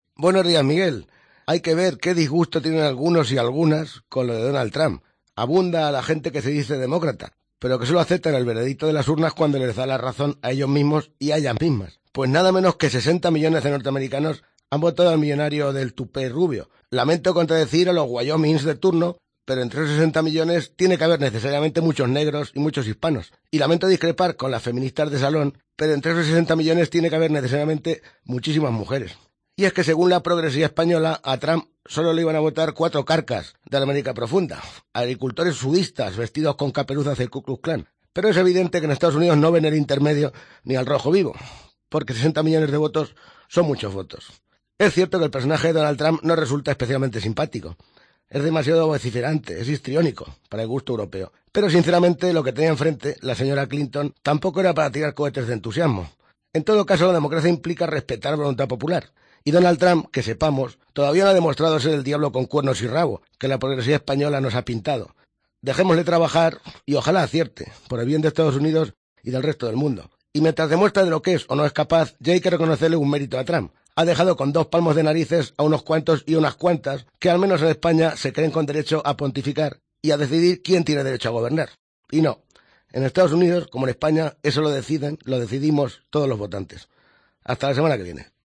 columna radiofónica